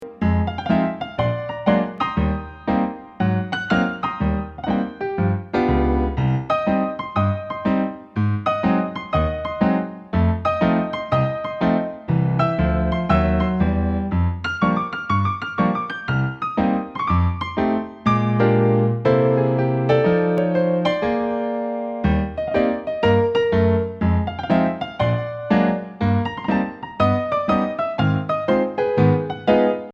Voicing: Jazz Keyboard